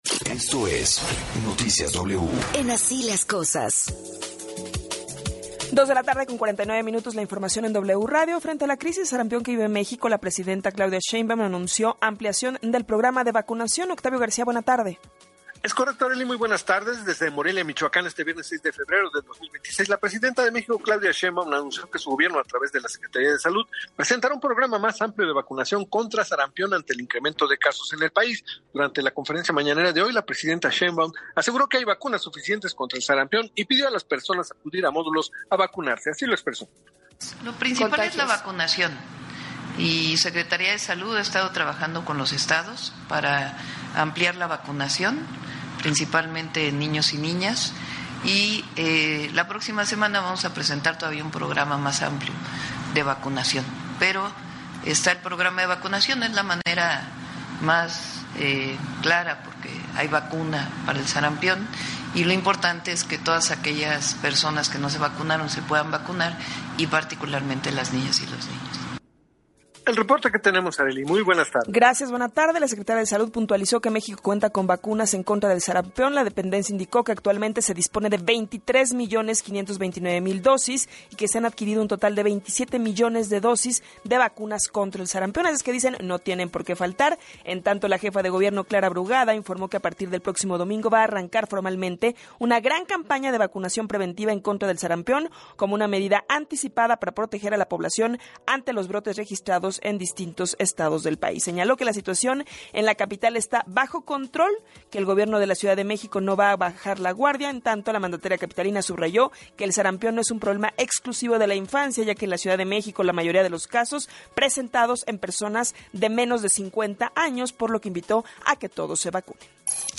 Resumen informativo